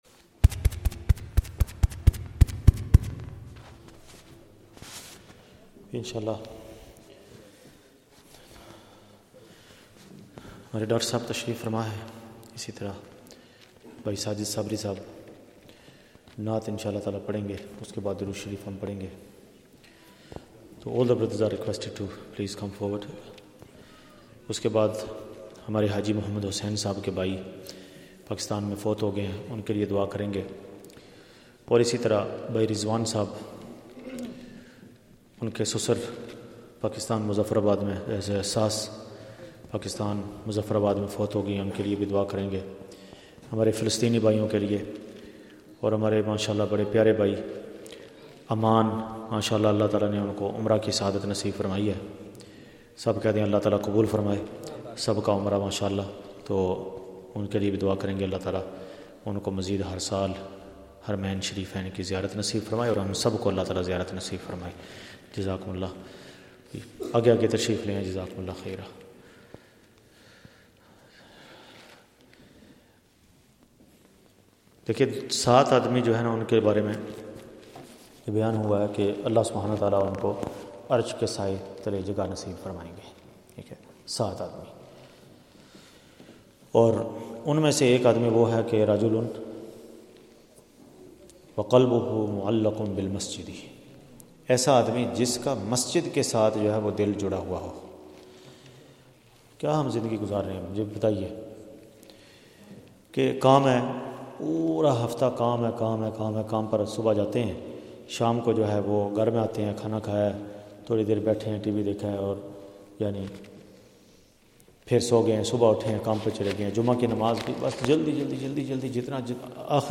Nashid & Drood majlis